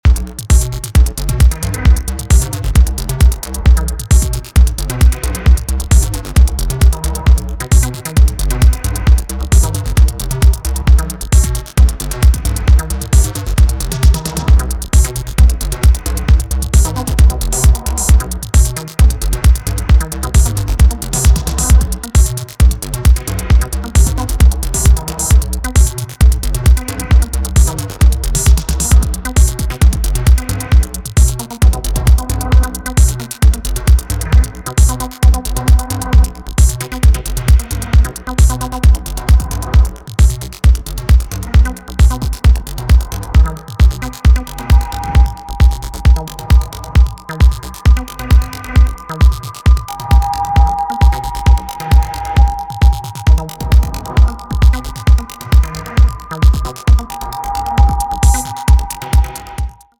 is a solid jam with old school Belgian techno flavor